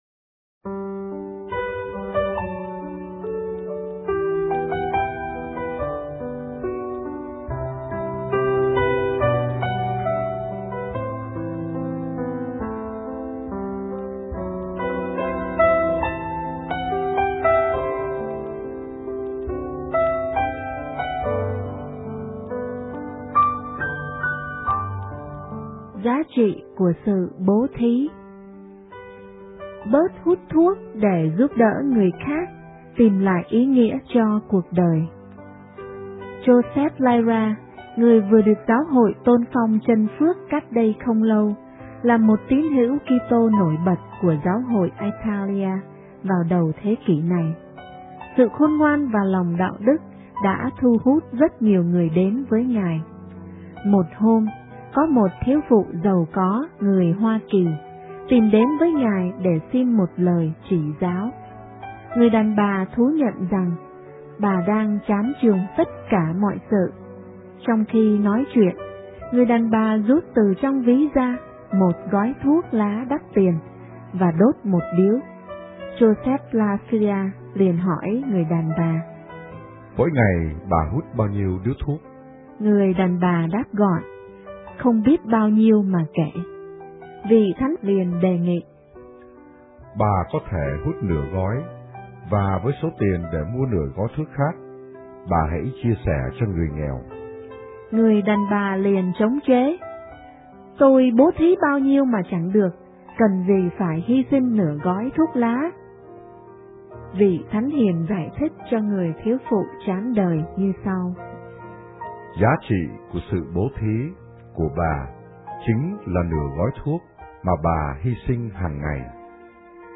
* Thể loại: Suy niệm